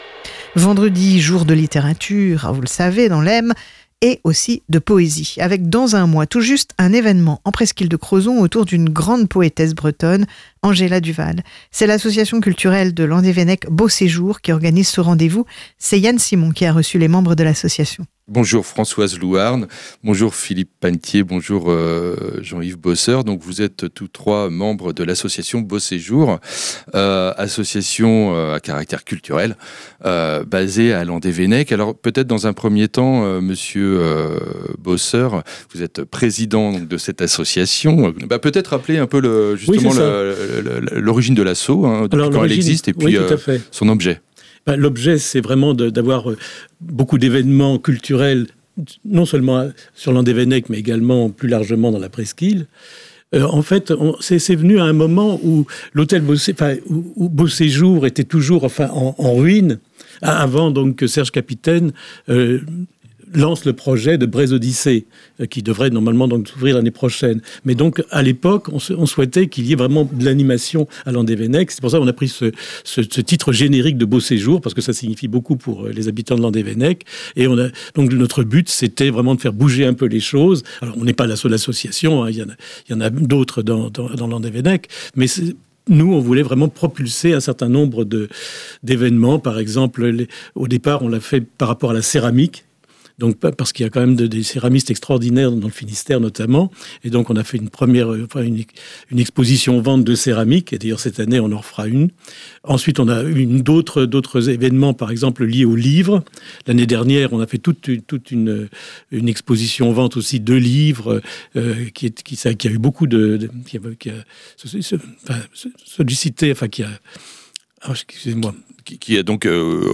Réécoutez l'interview de l'association Beauséjour